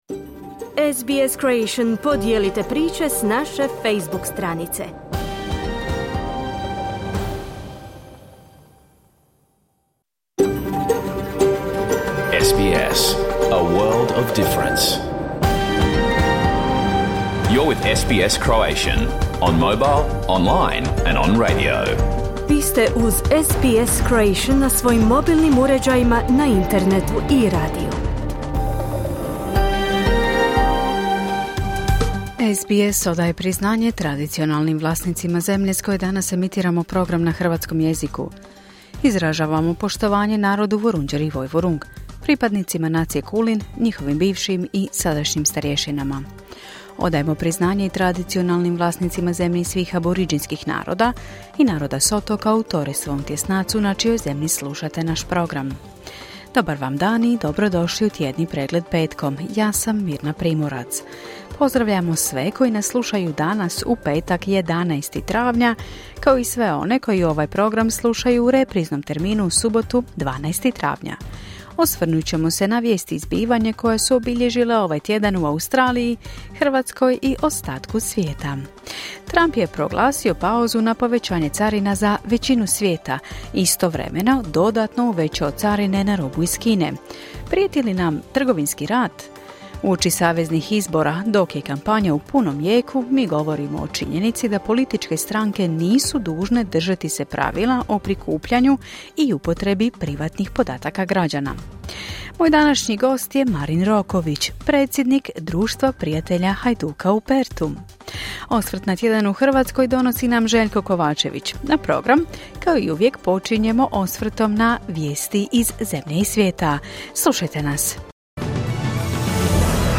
Vijesti, aktualne teme i razgovori iz Australije, Hrvatske i svijeta.